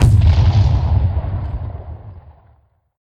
DeathFlash.wav